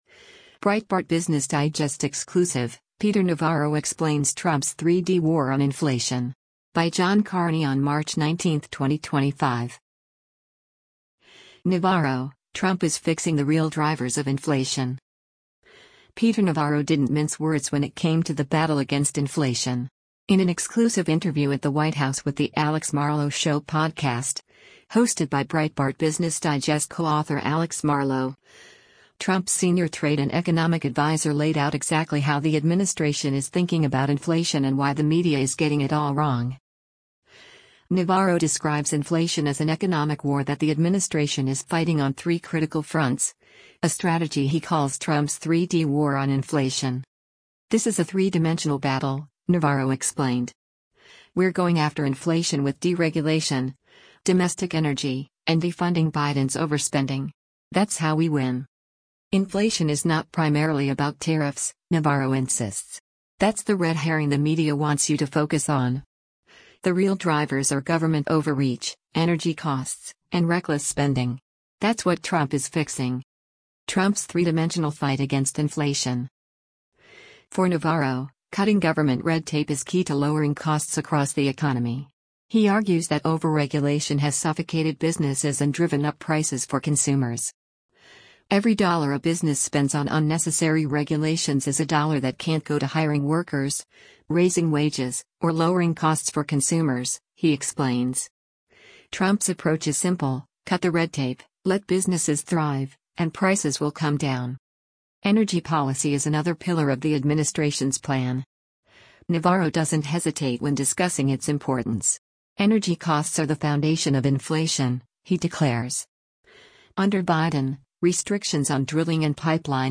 In an exclusive interview at the White House with The Alex Marlow Show podcast, hosted by Breitbart Business Digest co-author Alex Marlow, Trump’s senior trade and economic adviser laid out exactly how the administration is thinking about inflation and why the media is getting it all wrong.
This interview, conducted inside the White House, provides a critical insight into how Trump’s team is thinking about economic policy.